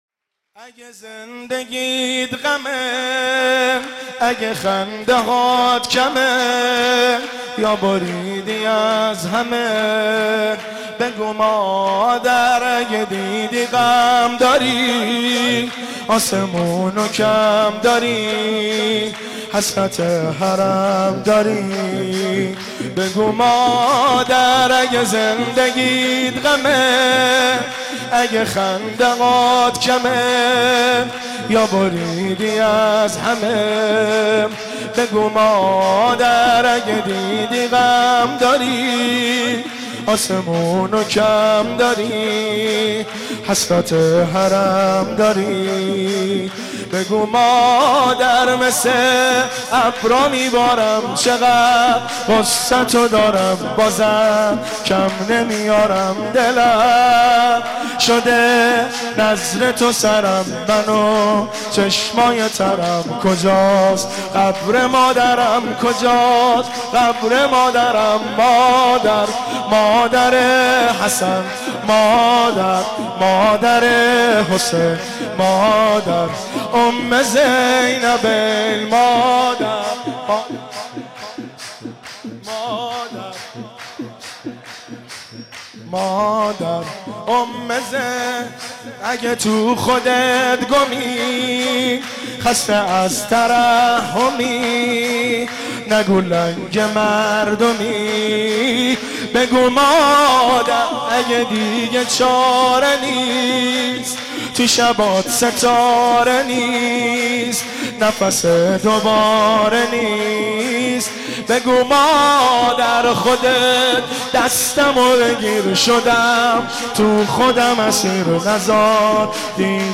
با مداحی